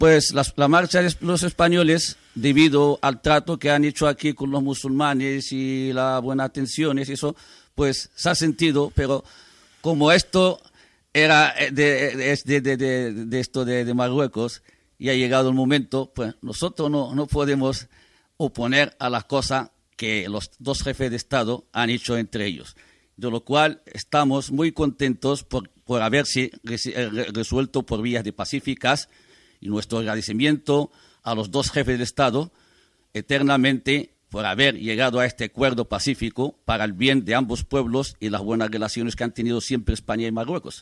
Informació de l'últim acte espanyol a la colònia de l'Ifni (Marroc) hores abans que aquesta passés a estar sota sobirania marroquina, Declaració del governador José Miguel Vega Rodríguez
Informatiu